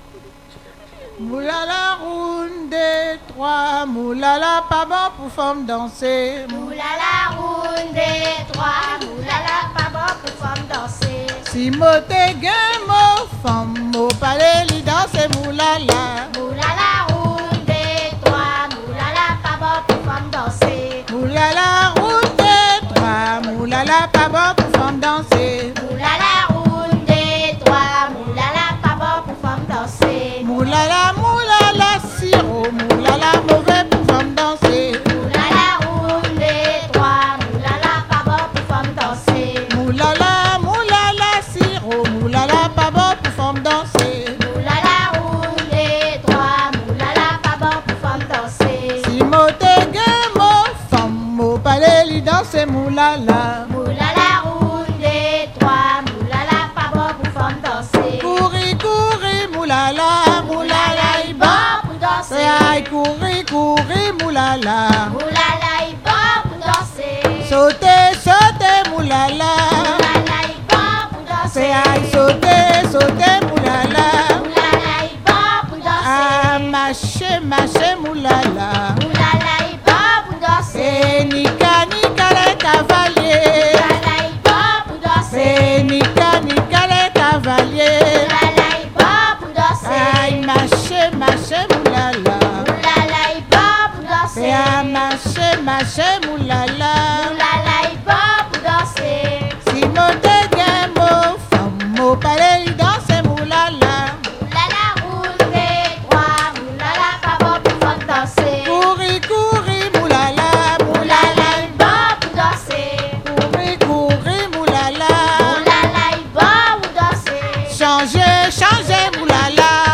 danse : moulala glisé (créole)
Pièce musicale inédite